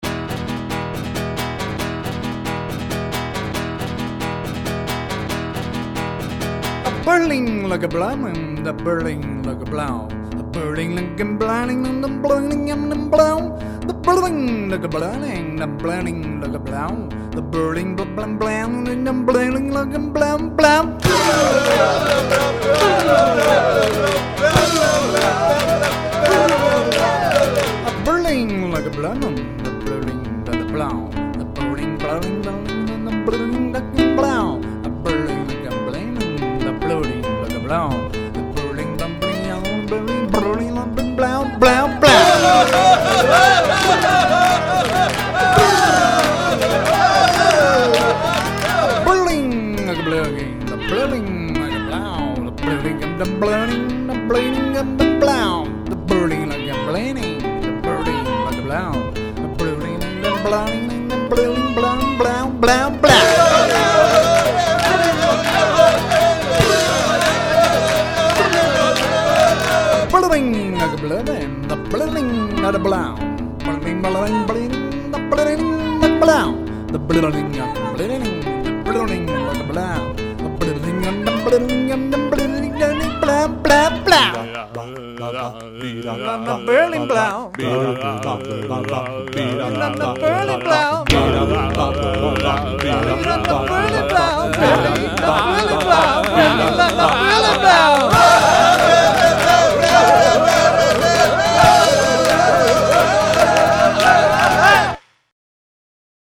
Su música la definen como omnivorous-jazz-dada experiment.
100% post-rock-jazz